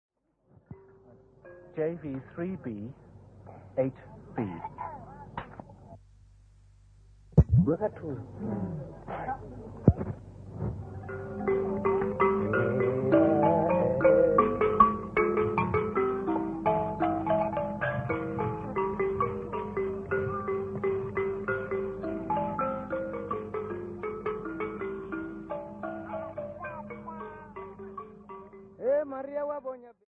chorus of women
Folk Music
Field recordings
Africa South Africa Limpopo Province f-sa
Indigenous music
96000Hz 24Bit Stereo